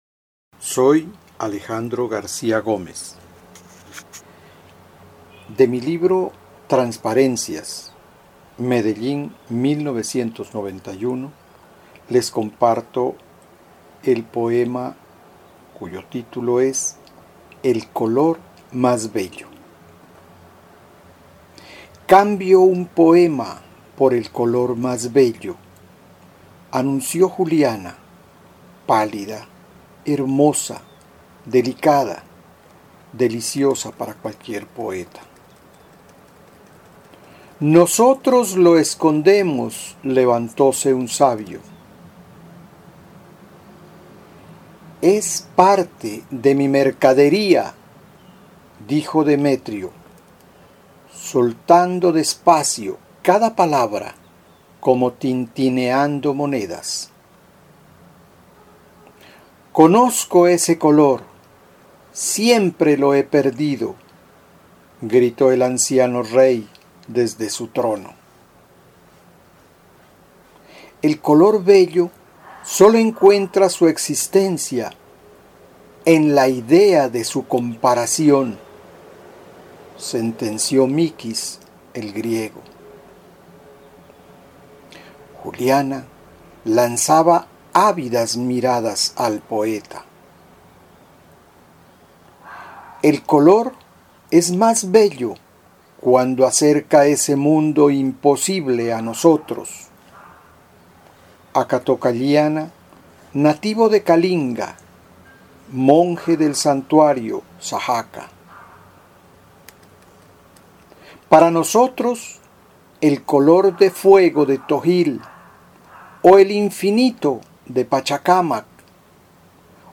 Poema “El color más bello”
poema-EL-COLOR-MAS-BELLO.mp3